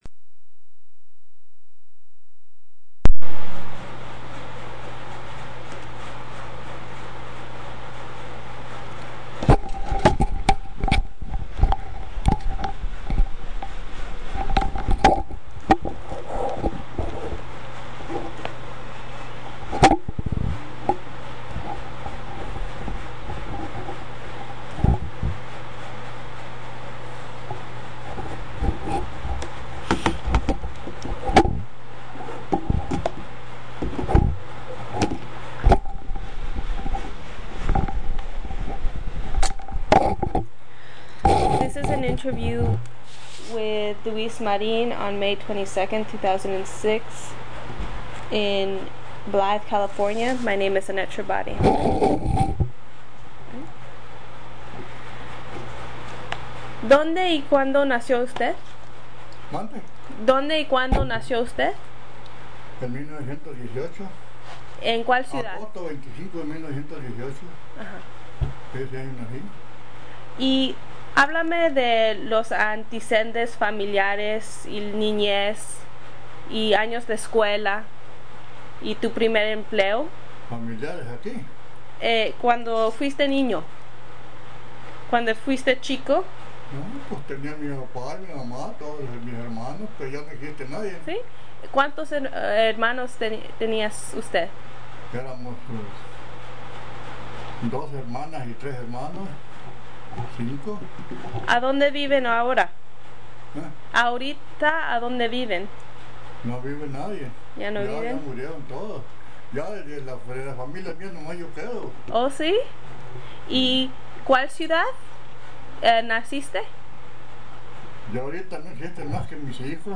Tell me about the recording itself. Location Blythe, CA Original Format Mini Disc